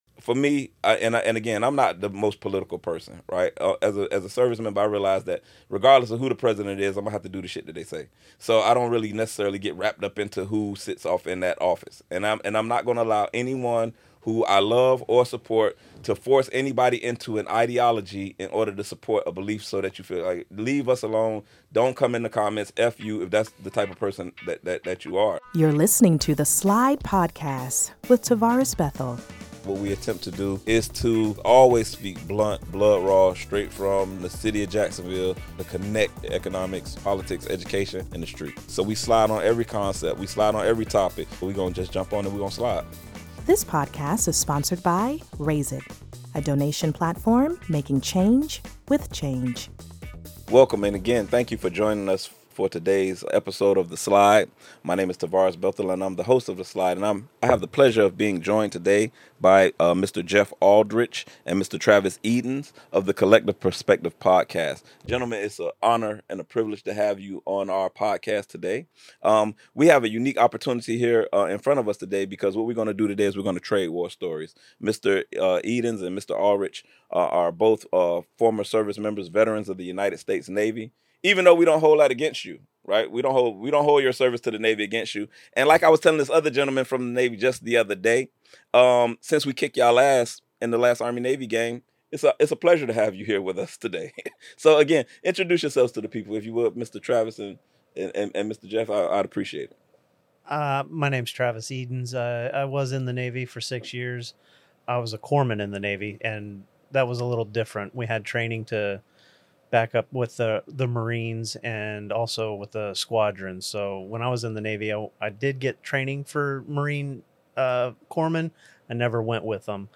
The conversation shares their experiences as veterans of the United States Navy with friendly banter between Army-Navy rivals.
Veterans' Voices: Bridging Divides and Sharing Perspectives This episode provides a unique opportunity to hear candid banter between veterans and aims to model unity and bridge societal divides.